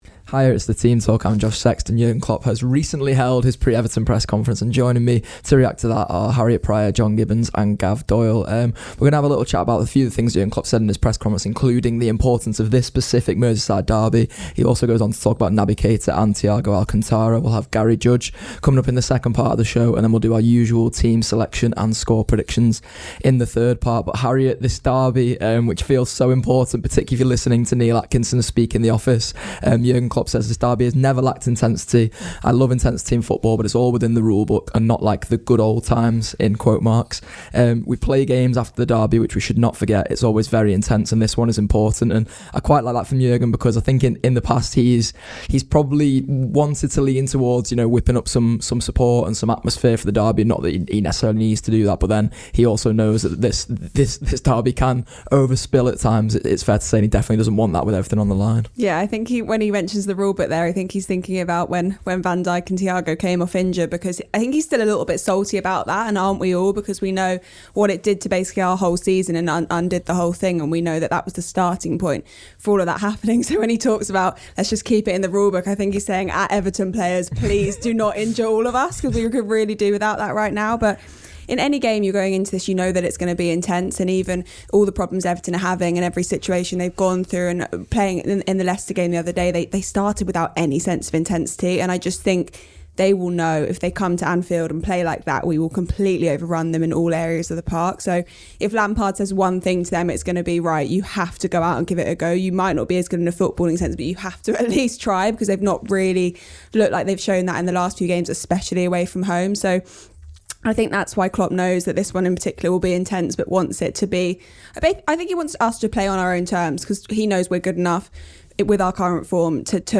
Below is a clip from the show – subscribe for more on the Liverpool v Everton press conference…